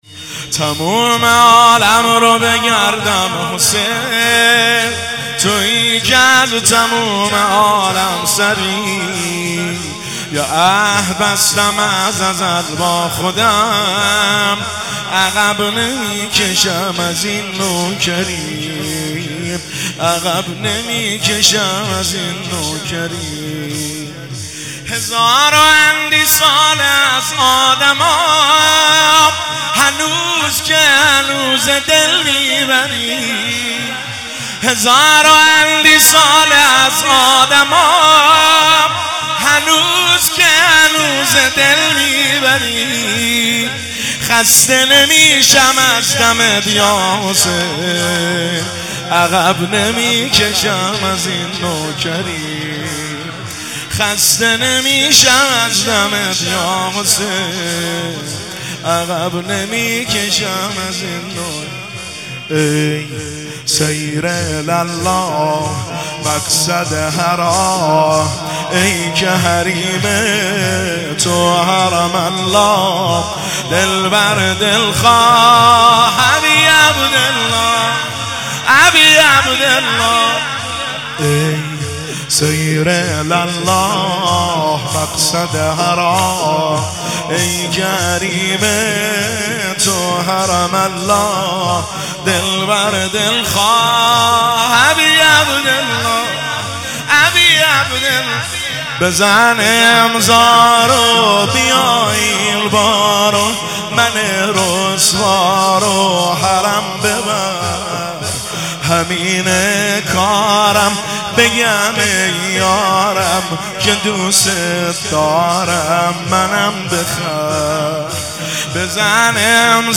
زمینه – شام وفات حضرت اُم البنین (س) 1403